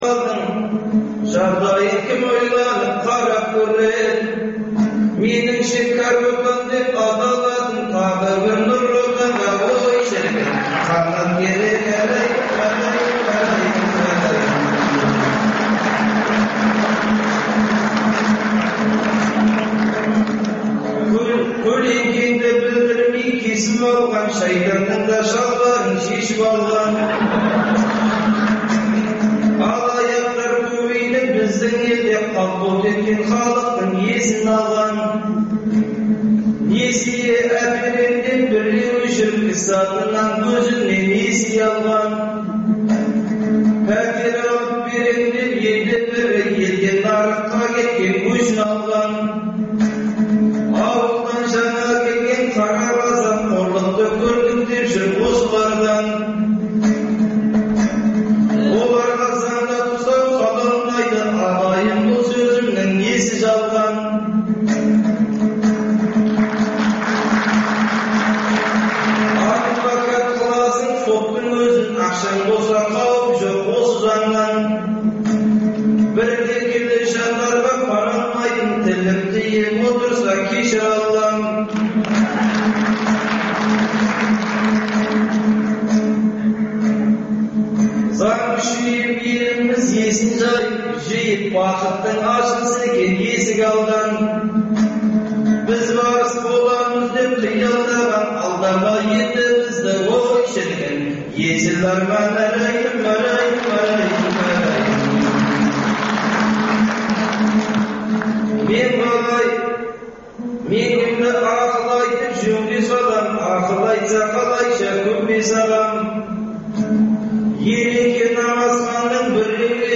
Өткен тарихты зерделеу, ақтаңдақтар мен ұлт тарихындағы қиын-қыстау күндердің бүгінгі тарихта бағалануы тұрғысында тарихшы – зерттеушілермен өткізілетін сұхбат, талдау хабарлар.